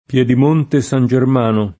pLHdim1nte San Jerm#no] (Lazio); Piedimonte Matese [